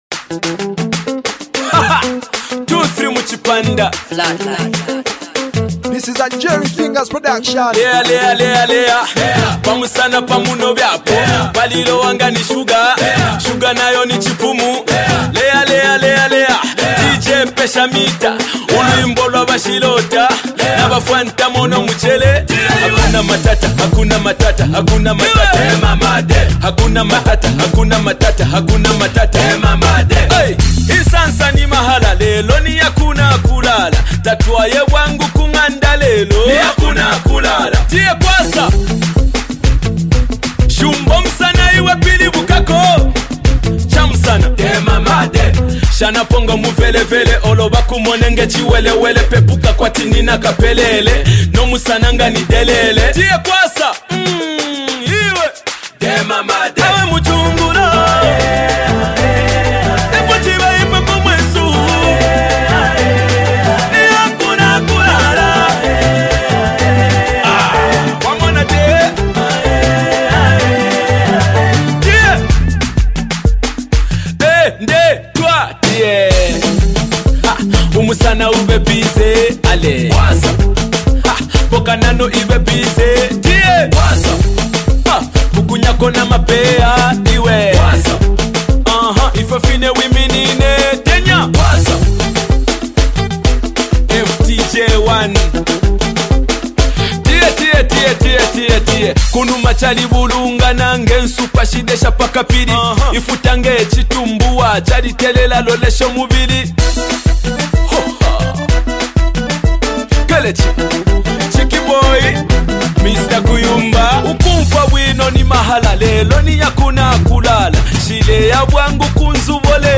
great danceable tune